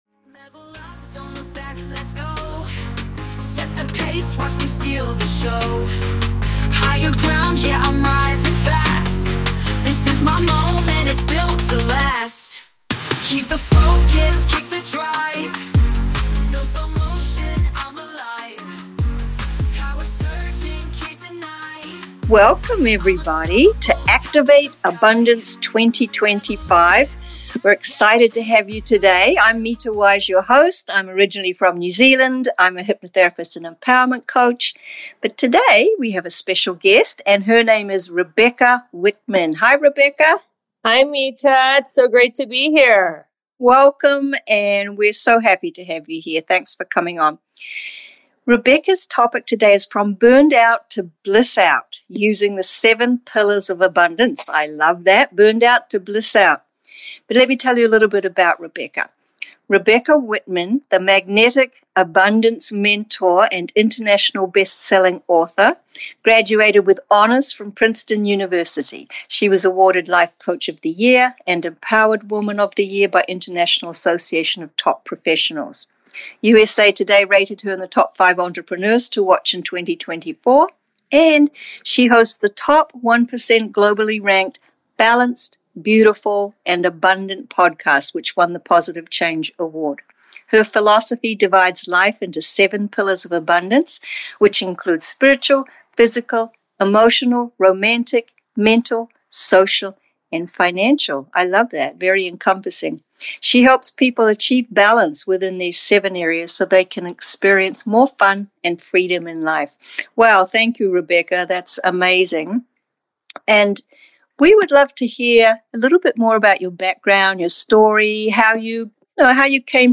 My interview on the Activate Abundance Summit